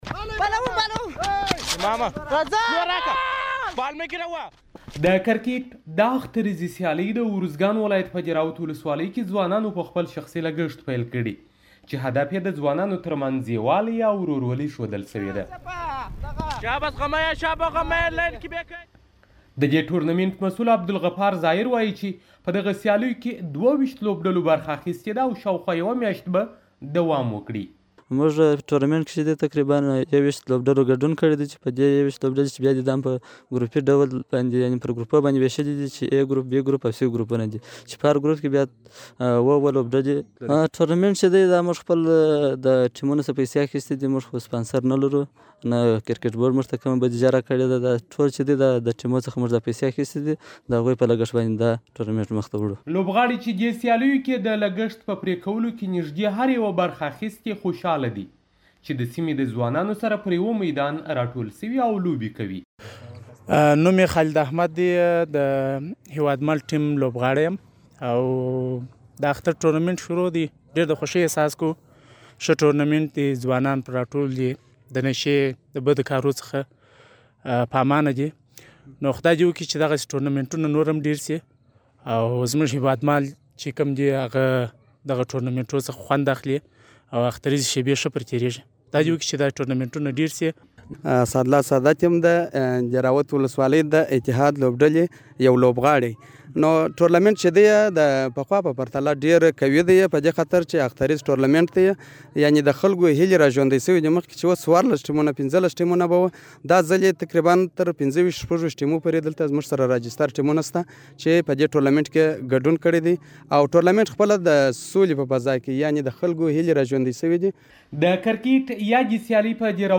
ارزګان کې د کرېکټ سياليو راپور